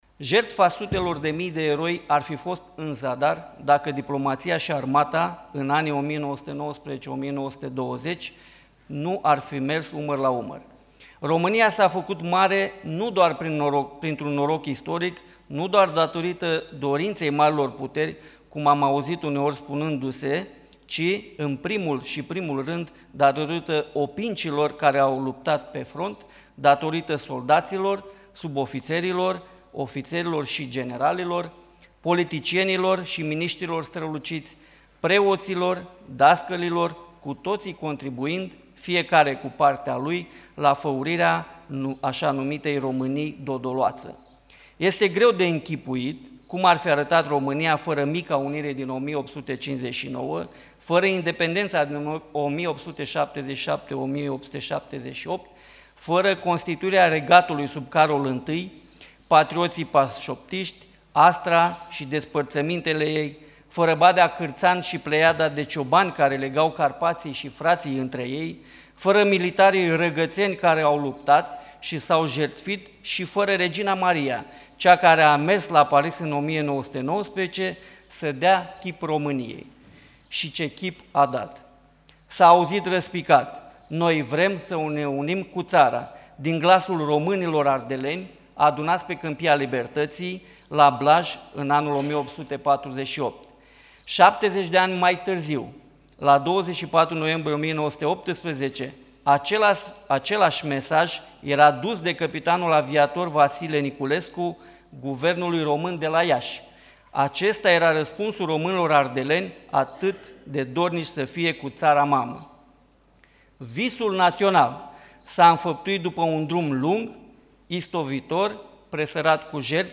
Fiecare, cu partea sa, a contribuit la faurirea asa-numitei Românii dodoloațe” – sublinia reprezentantul la vârf al Statului Major al Armatei, Director al Statului Major General, generalul dr.Corneliu Postu:
Astăzi, la deschiderea oficială a Conferinței internaționale ,,Contribuția Armatei României și a elitelor la apărarea și recunoașterea internațională a Marii Uniri (1919-1920)” ,  au mai fost prezenți reprezentanți ai Mitropoliei Moldovei şi Bucovinei, ai Diecezei Romano-Catolice de Iaşi, reprezentanţi ai Franţei şi ai Poloniei, cercetători ştiinţifici din ţară şi străinătate, experți pe problematica istoriei românilor și a Europei Centrale și de Sud-Est din state NATO (SUA, Marea Britanie, Franța, Italia, Germania, Polonia), majoritatea acestora fiind Aliați ai României în Razboiul de Întregire Națională și care au sprijinit realizarea Marii Uniri.